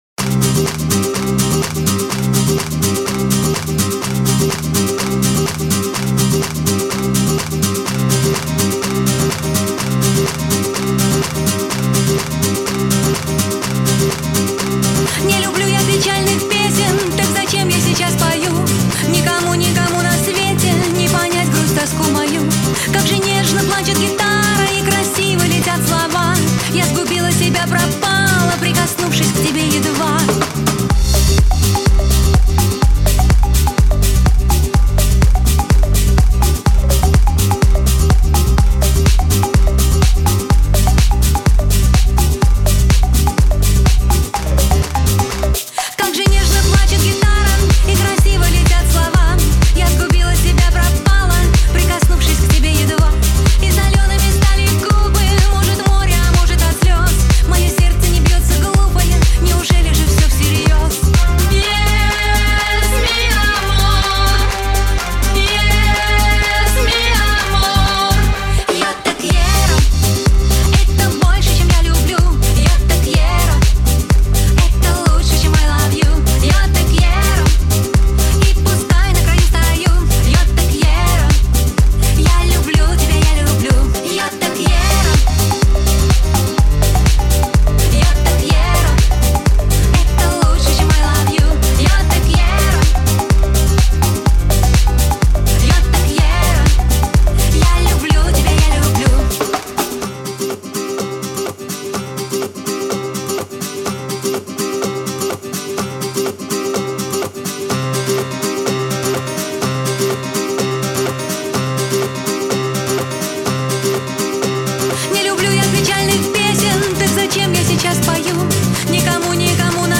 Жанр: Pop, Electronic, House
Стиль: House